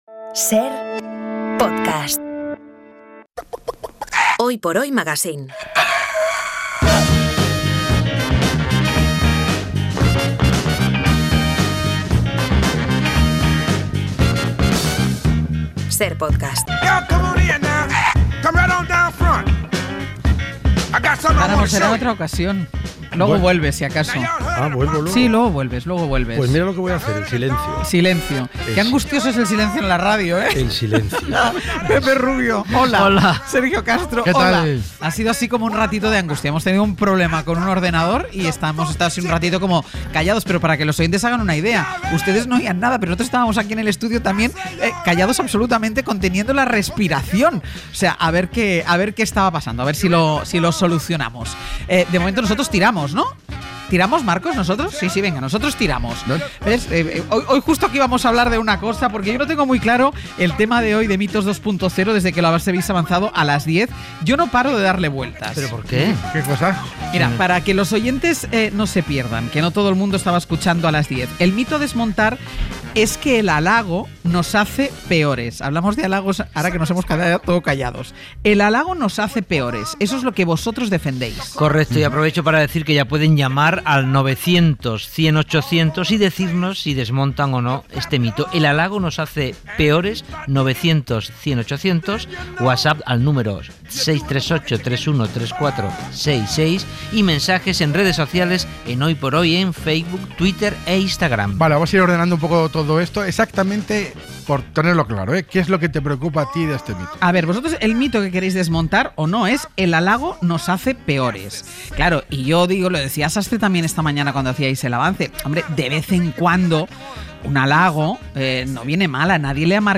La adulación sí que es dañina, es lo que conocemos como el 'peloteo' y siempre tiene una segunda intención. En la misma idea han ido todos los oyentes, le halago es por un trabajo o una acción bien hecha, comprobable.